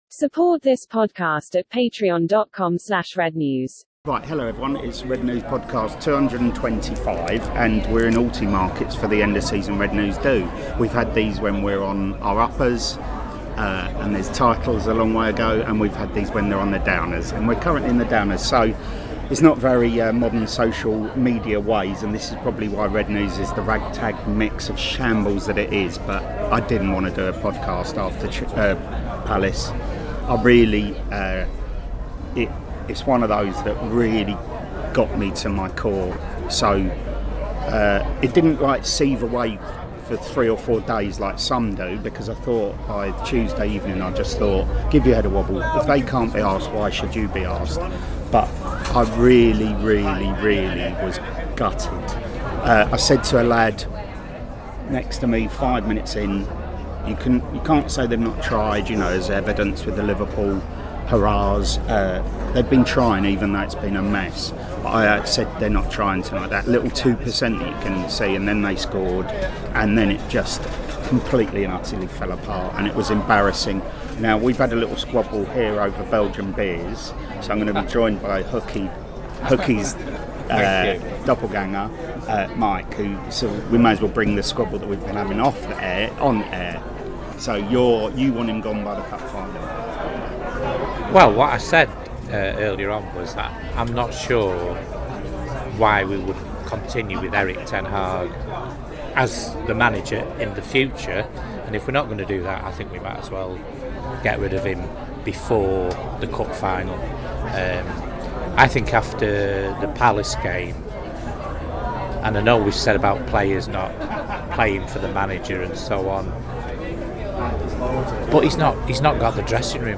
Reds at the end of season 'do'.
The independent, satirical Manchester United supporters' fanzine - for adults only, contains expletives, talks MUFC, or not at times, as we talk before and after the home defeat to Arsenal.